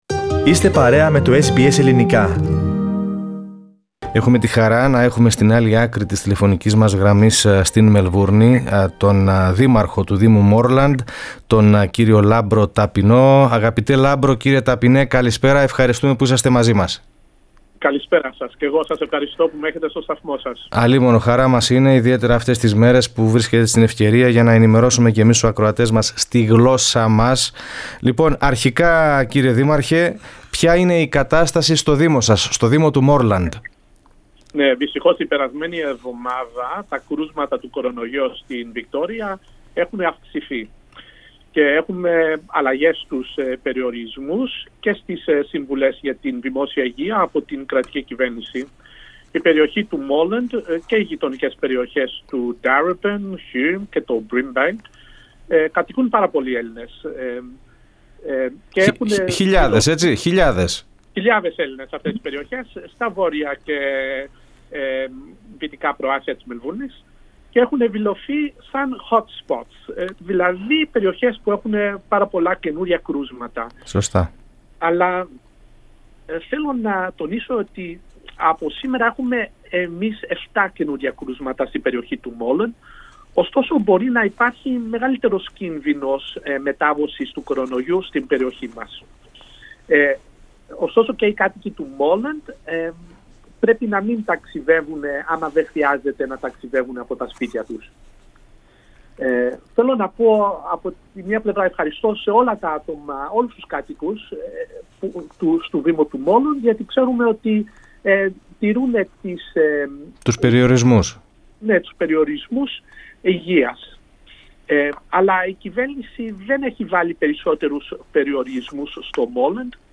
Ο δήμος Moreland είναι ανάμεσα στους έξι δήμους της Μελβούρνης με εστίες κορωνοϊού. Ο δήμαρχος Moreland Λάμπρος Ταπεινός μιλώντας στο SBS Greek είπε ότι ο πυρήνας κορωνοϊού στο δήμο αφορά οικογένεια στην περιοχή του Coburg.